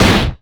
IMPACT_Generic_06_mono.wav